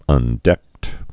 (ŭn-dĕkt)